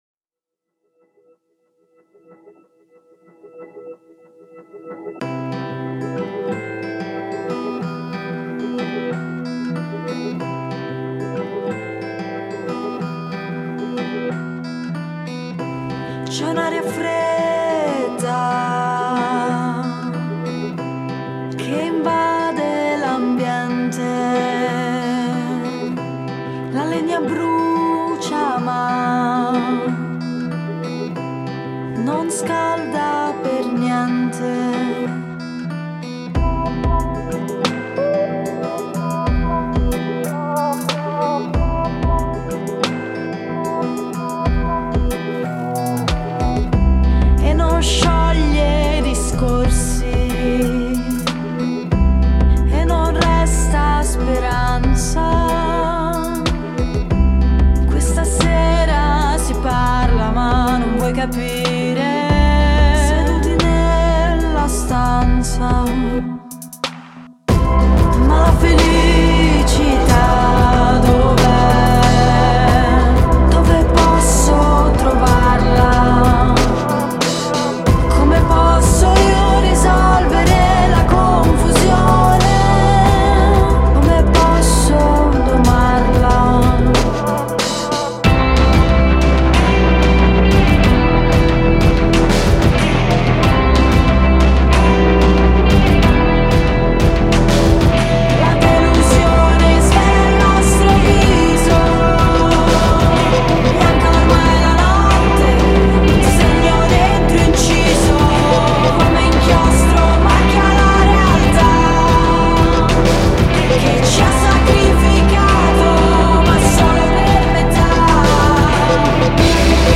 chitarra